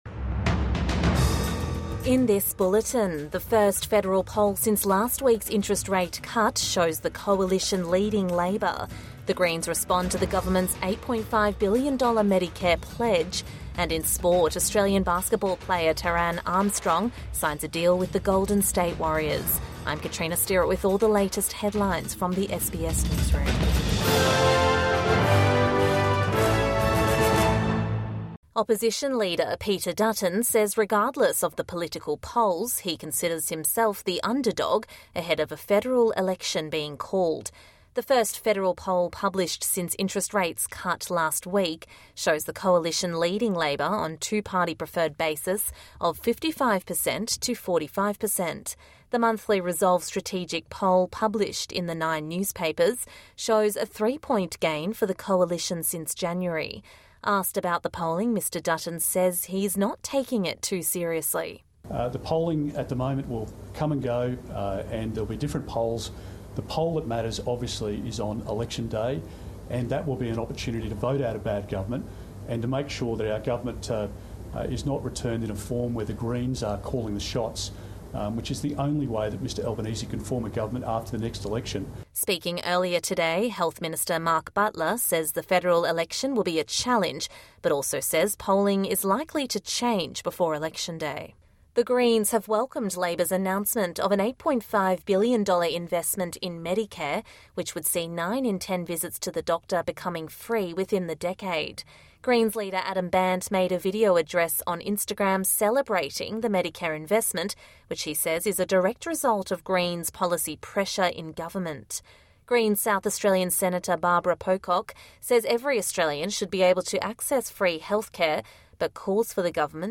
Evening News Bulletin 24 February 2025